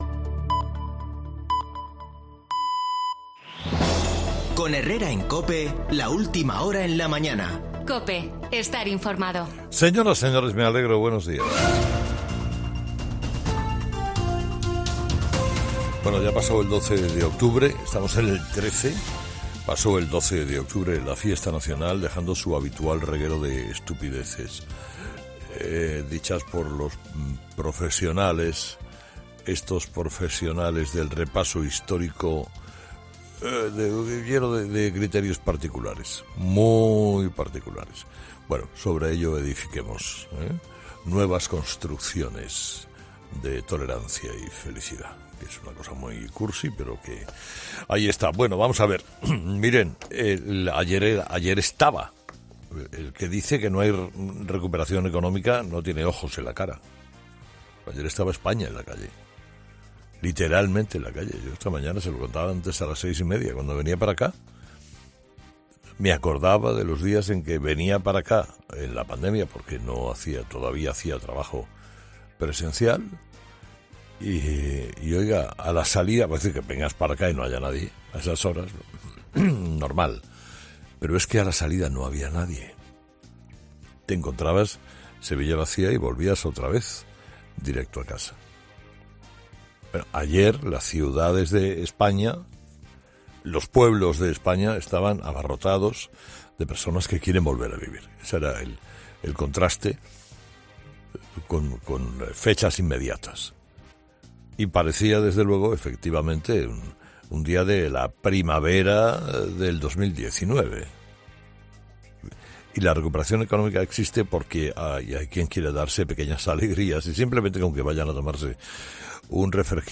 [ESCUCHA AQUÍ EL MONÓLOGO DE HERRERA DEL 13 DE OCTUBRE]